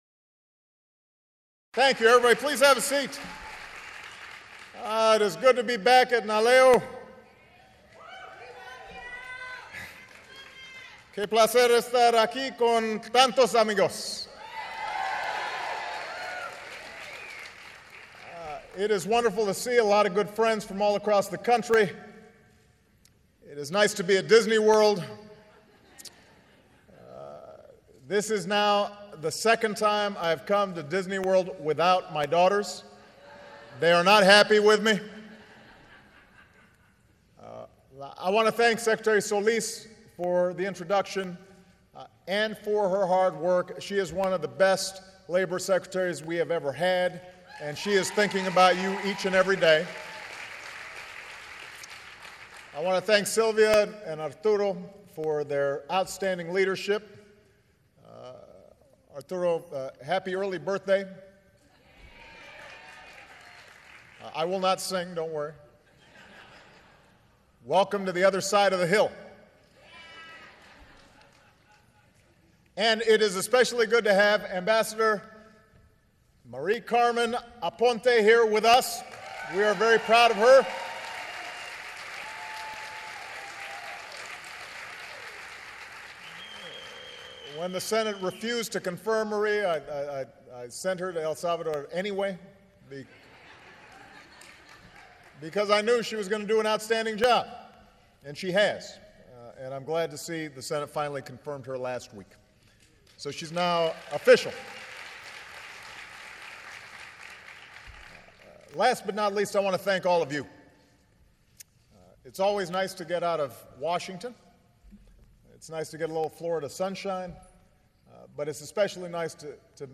U.S. President Barack Obama speaks to the NALEO Annual Conference